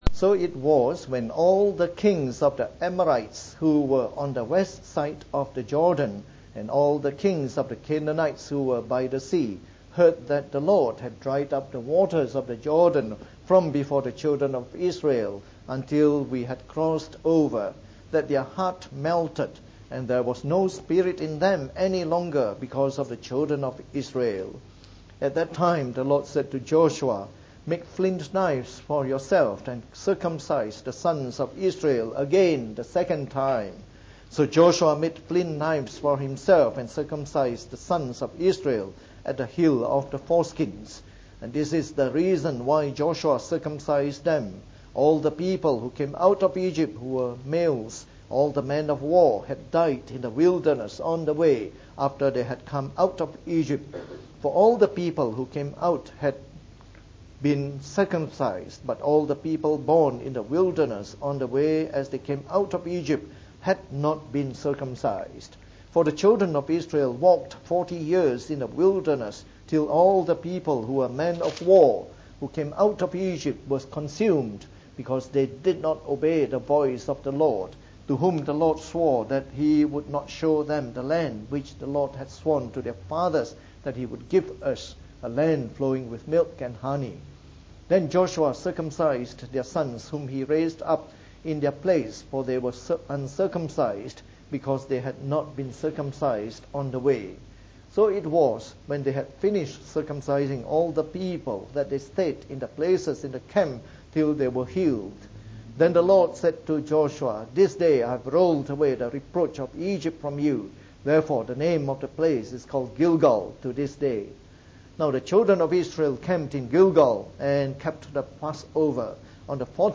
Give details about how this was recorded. From our series on the Book of Joshua delivered in the Morning Service.